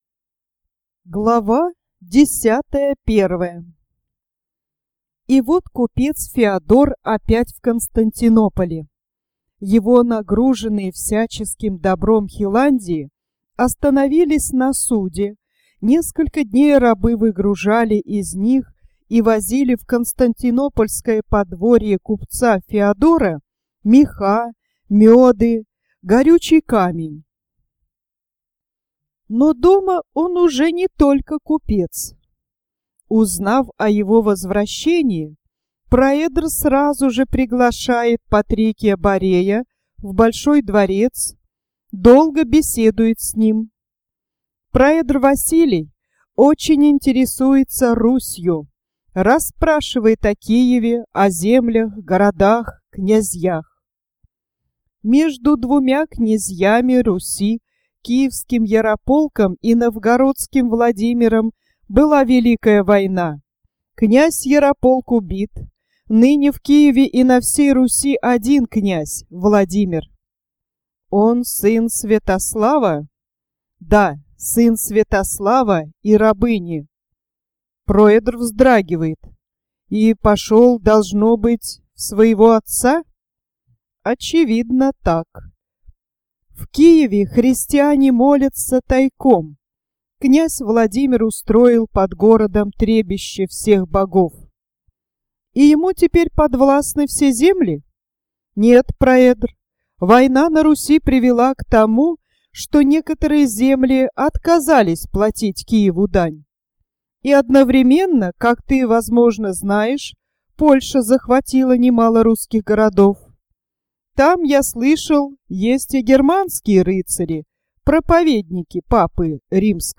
Исторический аудио роман украинского советского писателя Семёна Дмитриевича Скляренко (1901 - 1962 годы жизни).